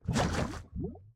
Minecraft Version Minecraft Version snapshot Latest Release | Latest Snapshot snapshot / assets / minecraft / sounds / mob / strider / step_lava3.ogg Compare With Compare With Latest Release | Latest Snapshot
step_lava3.ogg